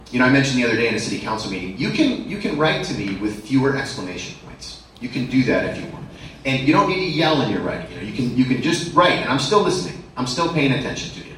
On Friday, the Dubuque Area Chamber of Commerce hosted their semi-annual Tri-State Mayors Breakfast with mayors or leading officials of six local cities.